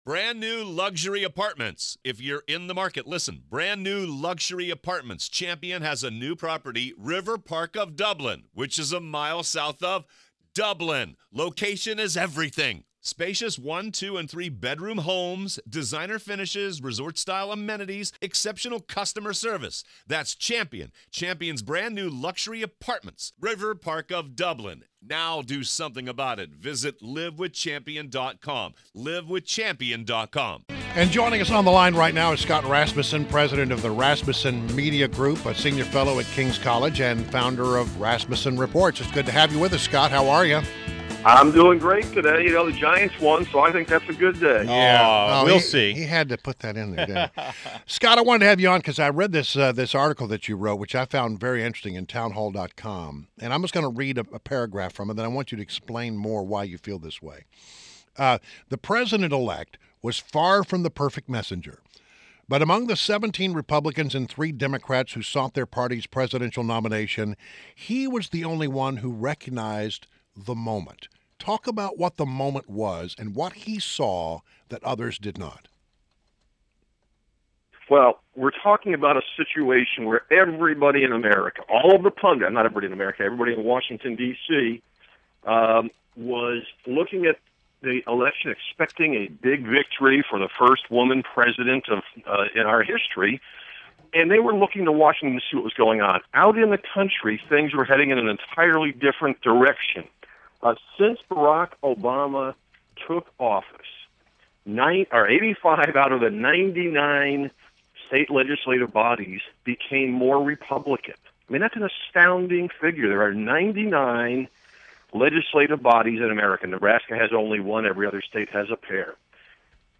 INTERVIEW — SCOTT RASMUSSEN – President, Rasmussen Media Group; Sr Fellow at King’s College and Founder of Rasmussen Reports